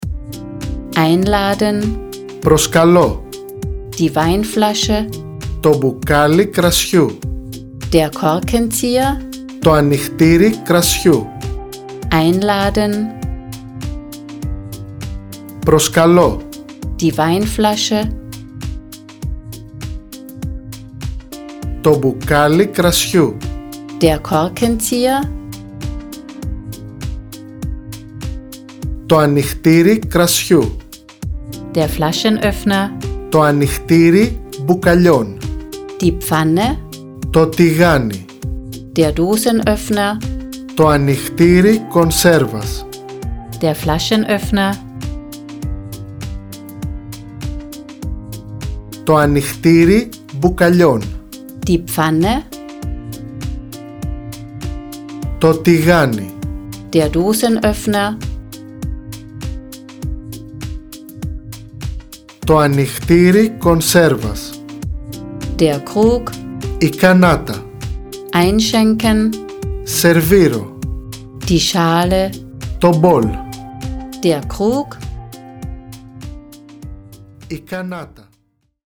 Mit normaler Hintergrund-Musik:
Und Sie haben immer den perfekten Native-Speaker zur Kontrolle und Verbesserung Ihrer Aussprache dabei.
Sie hören zuerst das deutsche Vokabel und danach die griechische Übersetzung. Nach drei Vokabeln wird der soeben gelernte Block mit einer Sprechpause, in der Sie die Übersetzung laut aussprechen, abgeprüft.